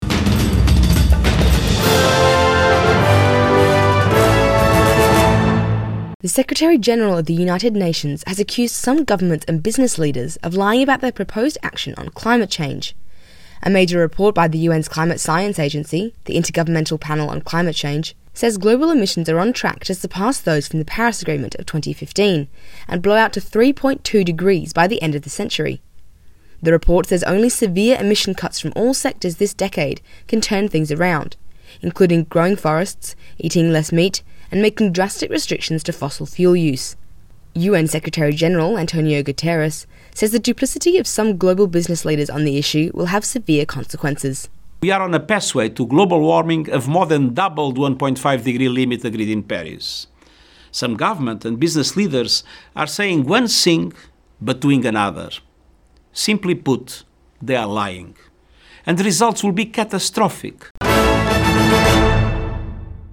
من مراسلينا: أخبار لبنان في اسبوع 5/04/2022